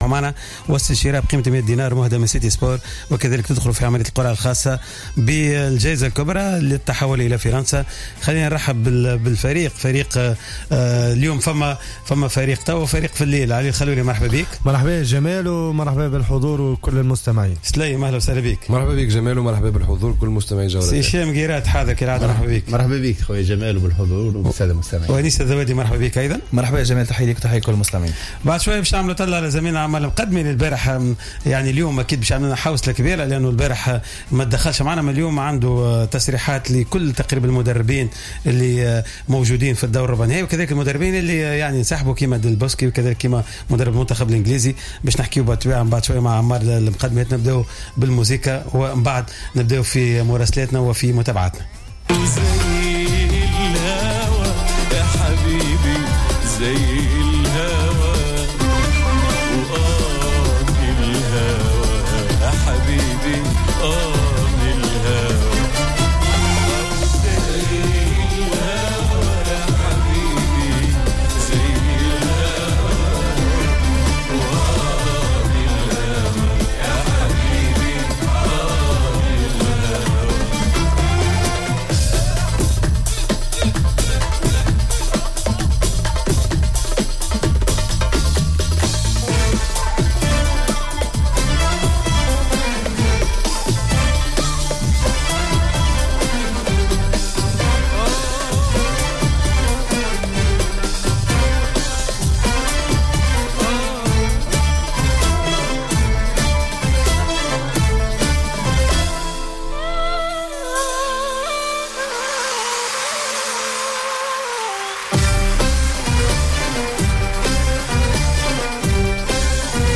مباشرة من باريس